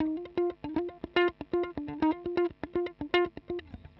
120_Guitar_funky_riff_D_1.wav